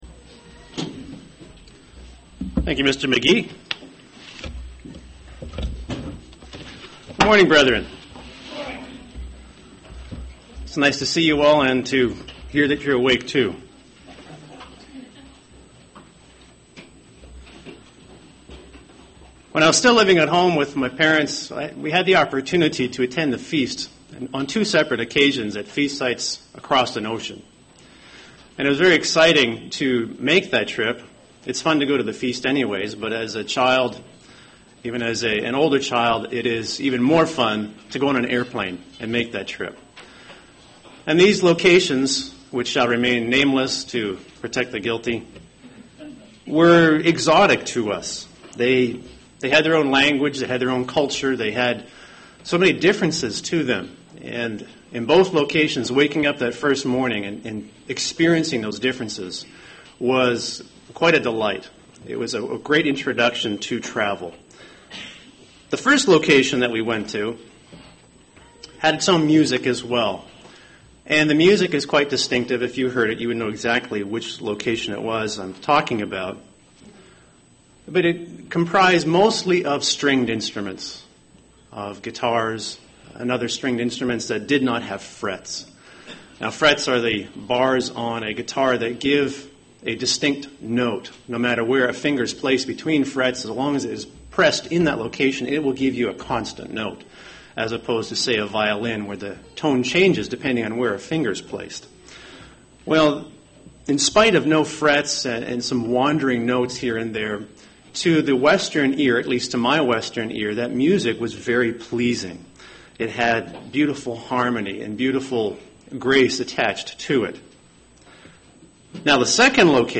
In this Sermon we will examine how to be peacemakers.